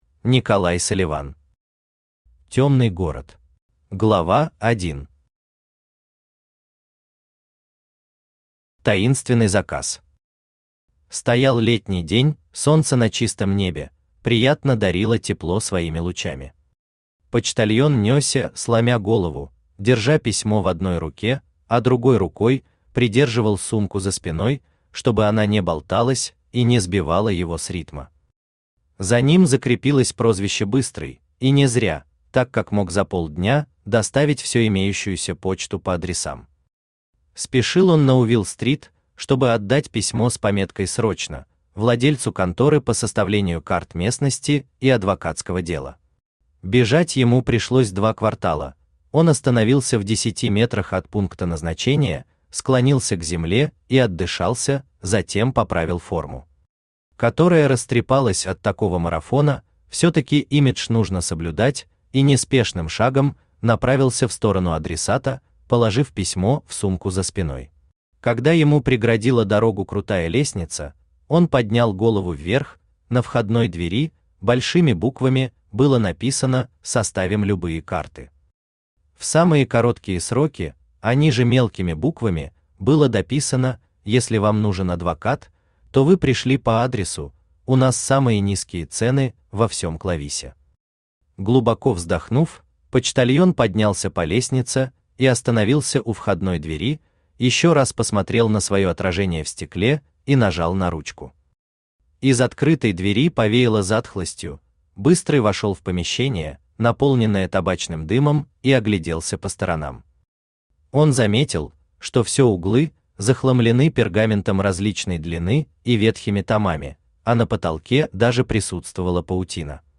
Аудиокнига Тёмный город | Библиотека аудиокниг
Aудиокнига Тёмный город Автор Николай Владимирович Салливан Читает аудиокнигу Авточтец ЛитРес.